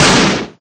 Ice7.ogg